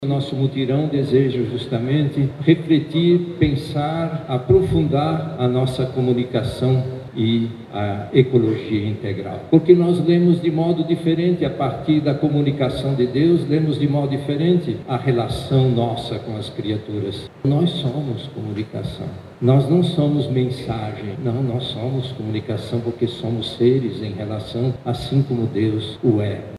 Durante a homilia, o Arcebispo Metropolitano de Manaus, Cardeal Leonardo Steiner, deu as boas-vindas aos participantes, vindos tanto de locais próximos quanto distantes, e destacou a importância da reflexão: “Cada criatura é comunicação de Deus”.